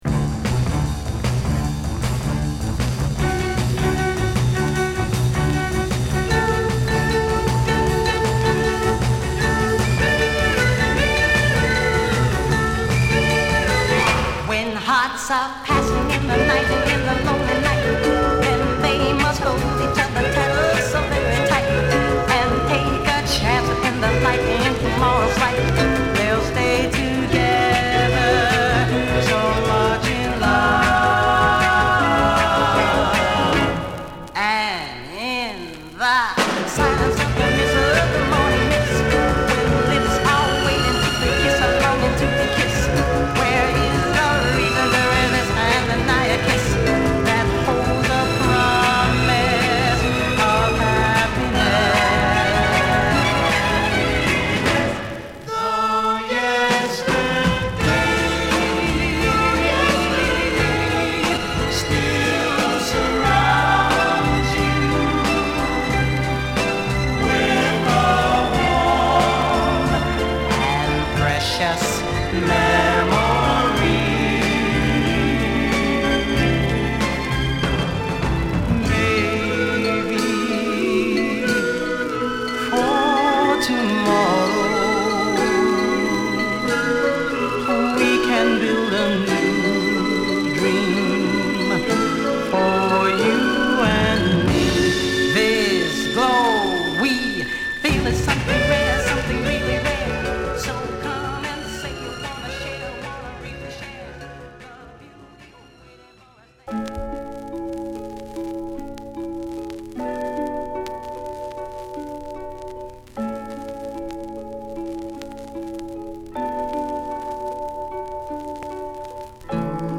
ドゥーワップ〜ソウル色は薄く、ラウンジな仕上がりです！